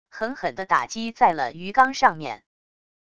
狠狠的打击在了鱼缸上面wav音频